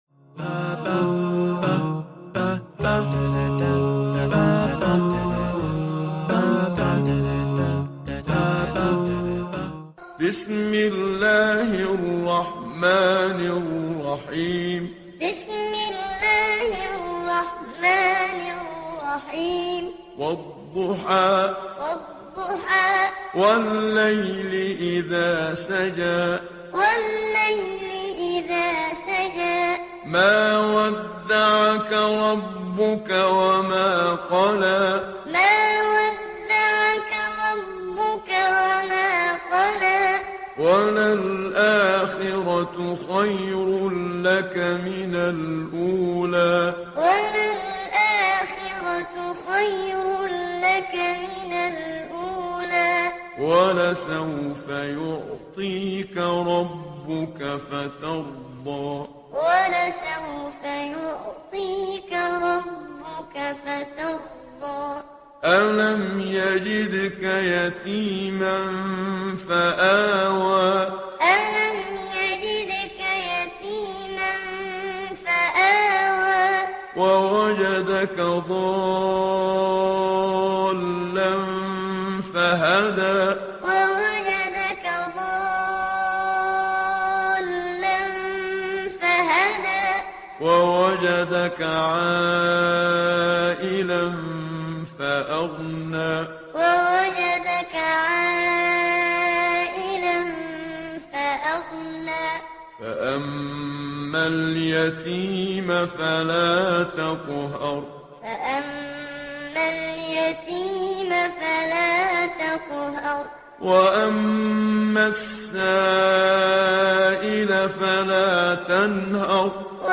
093 - Al-Duha - Qur'an Time - Read Along.mp3